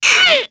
Lakrak-Vox_Damage_jp_02.wav